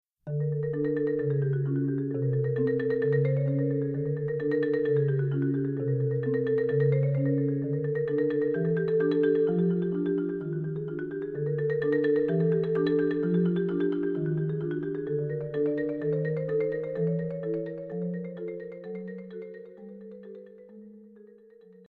マリンバ